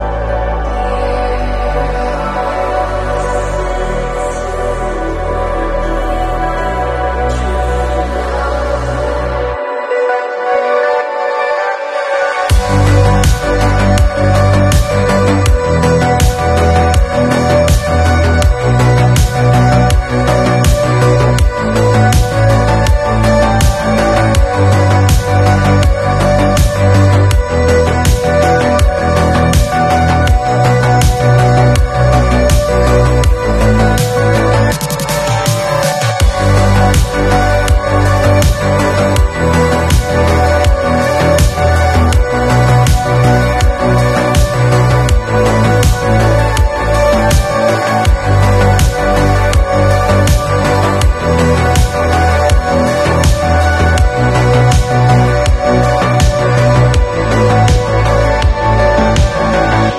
Continuing with the Blade Runner inspired sound effects free download
cyberpunk aesthetic